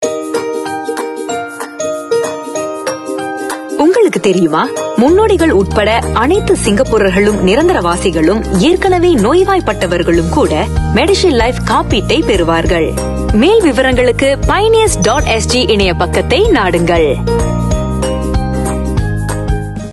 Voice Samples: Voice Sample 03
female